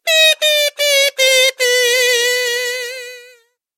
Звуки казу
Откройте для себя завораживающие звуки казу – музыкального инструмента с необычным тембром.
Грусть музыкальный инструмент